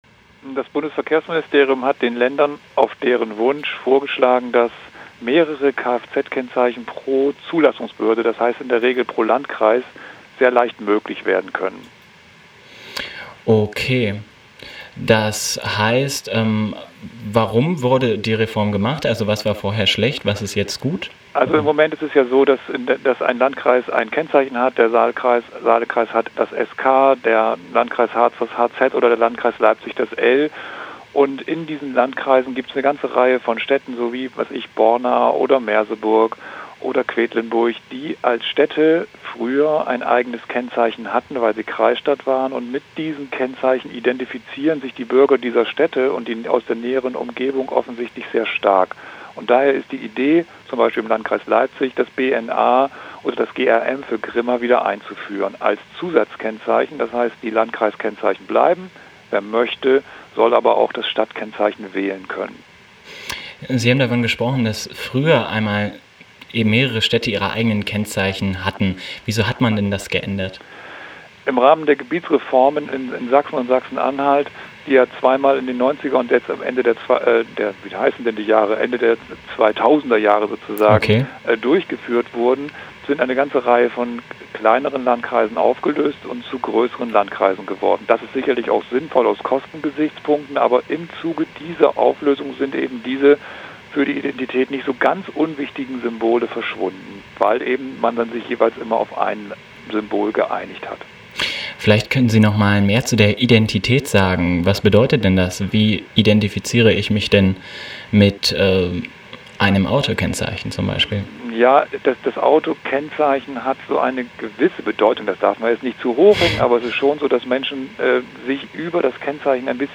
am Telefon zu Rückkehr der alten Ortskenzeichen